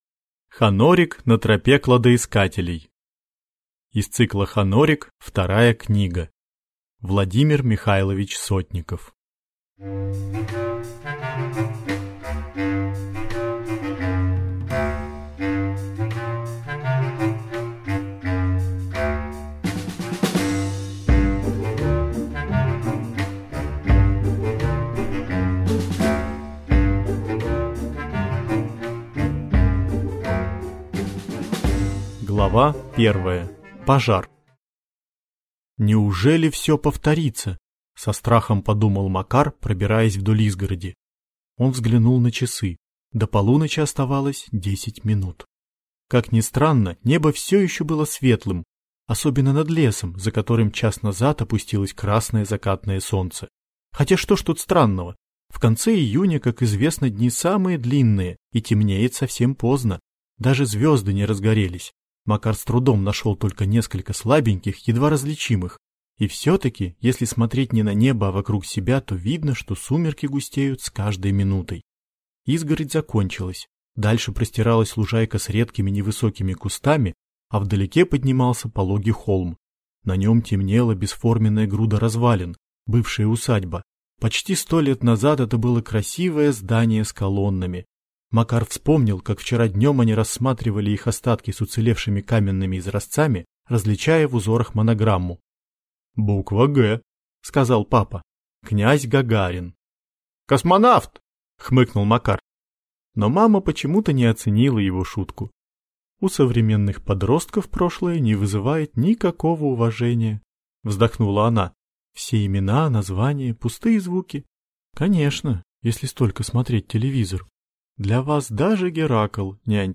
Аудиокнига Хонорик на тропе кладоискателей | Библиотека аудиокниг